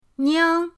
niang2.mp3